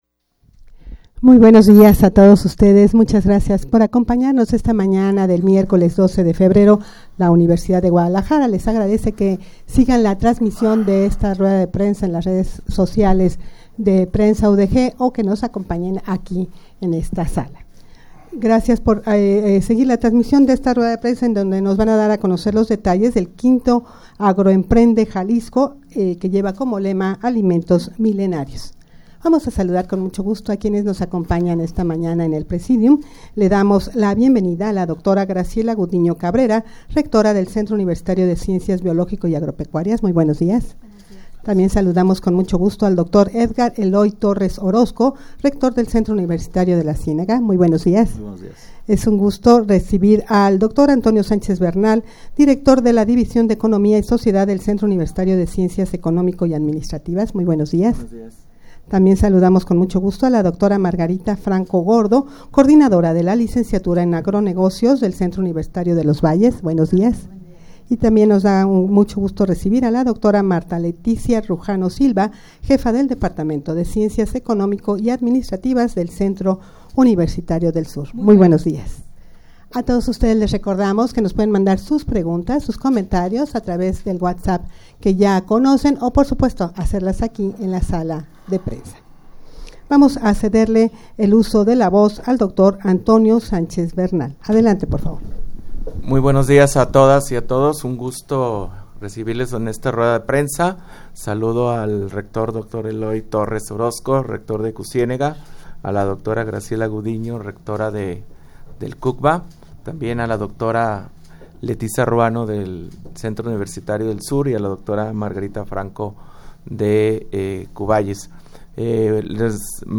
rueda-de-prensa-para-dar-a-conocer-los-detalles-de-5to-agroemprende-jalisco-alimentos-milenarios_0.mp3